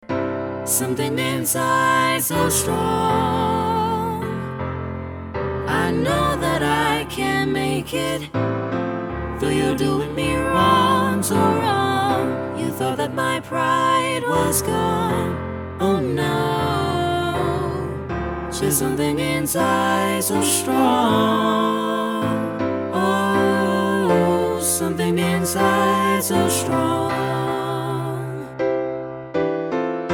A bold, defiant SAB arrangement